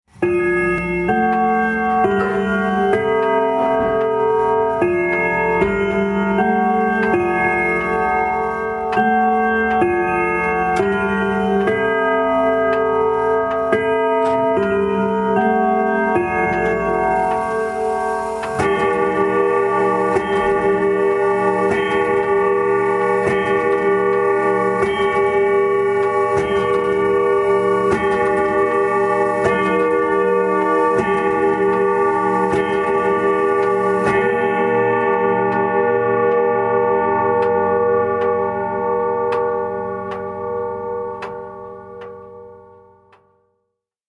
دانلود آهنگ ساعت 27 از افکت صوتی اشیاء
جلوه های صوتی